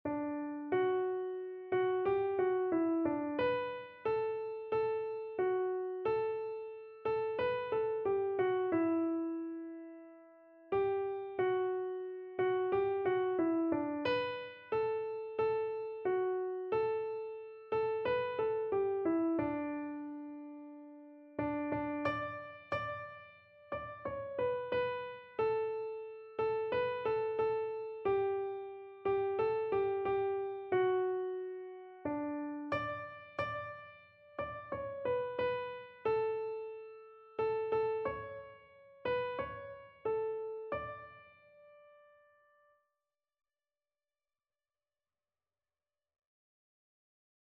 Christian Christian Keyboard Sheet Music I Know Whom I Have Believed
Free Sheet music for Keyboard (Melody and Chords)
4/4 (View more 4/4 Music)
D major (Sounding Pitch) (View more D major Music for Keyboard )
Keyboard  (View more Intermediate Keyboard Music)
Classical (View more Classical Keyboard Music)